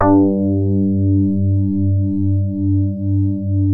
JAZZ MID  G1.wav